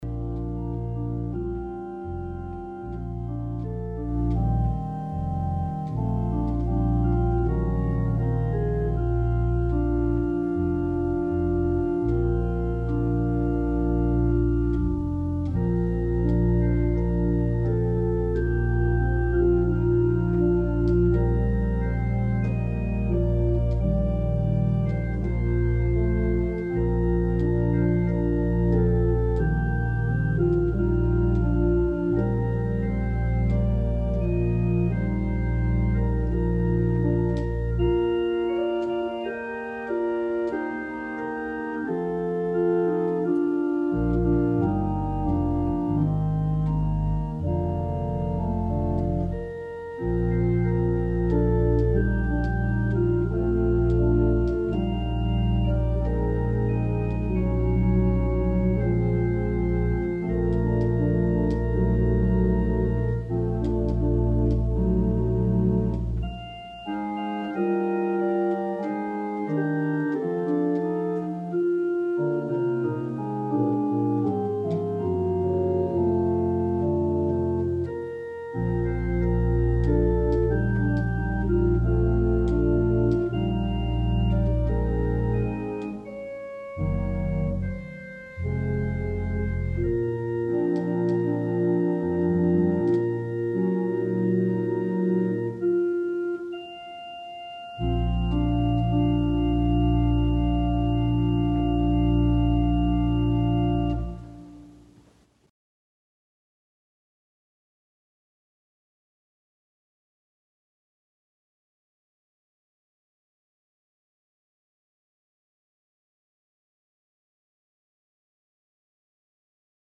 We held virtual worship on Sunday, January 3, 2021 at 10:00am.